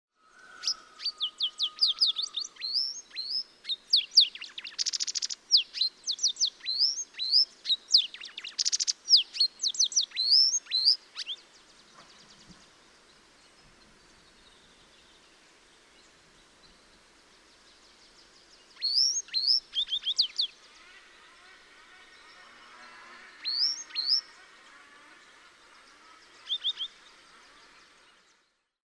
Az özvegy billegető (Motacilla aguimp) hangja
• A billegetők hangja általában csicsergő, trillázó, néha éles hangokból áll. Az özvegy billegető hangja is hasonló jellemzőket mutat.
• Gyors, ismétlődő hangok.
• Magas hangfekvés.
Csicsergő, trillázó, néha éles hangok.
ozvegy-billegeto-hangja.mp3